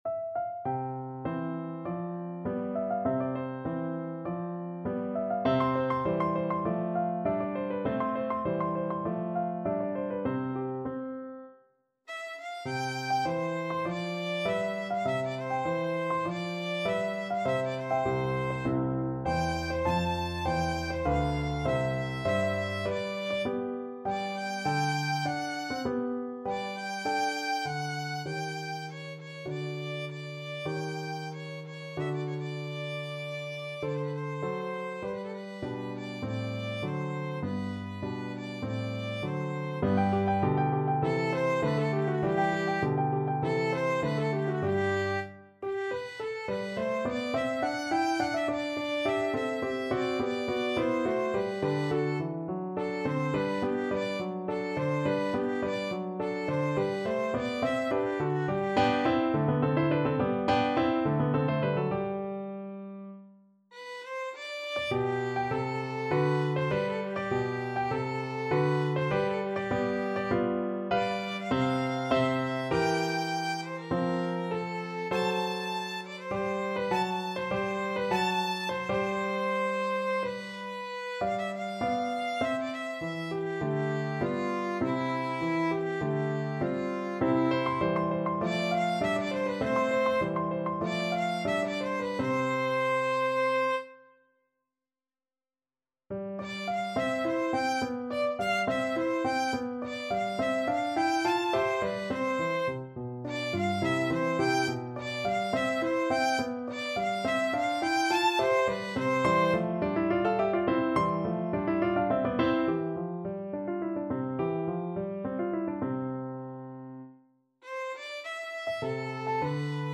Classical Pergolesi, Giovanni Battista A Serpina Penserete from La Serva padrona Violin version
2/4 (View more 2/4 Music)
~ = 50 Larghetto
C major (Sounding Pitch) (View more C major Music for Violin )
Classical (View more Classical Violin Music)